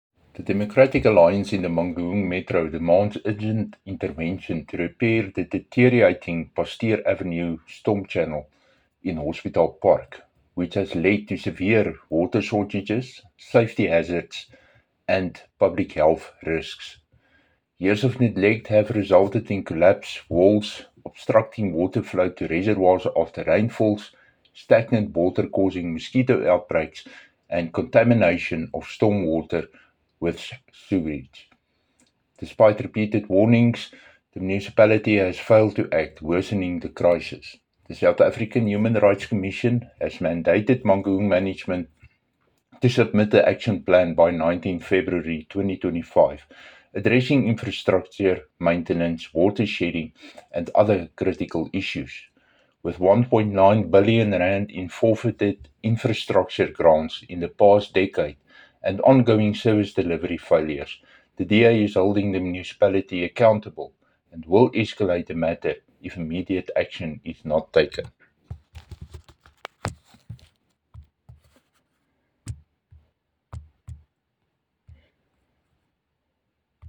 English and Afrikaans soundbites by Cllr Dirk Kotze and